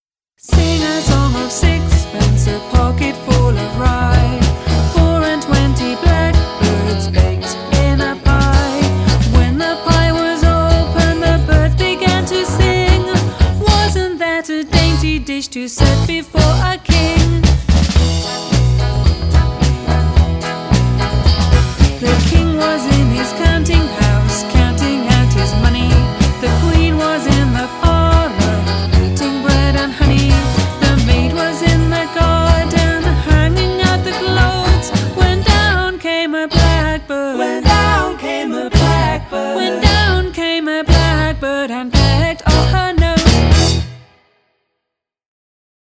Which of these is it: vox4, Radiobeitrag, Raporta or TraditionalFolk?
TraditionalFolk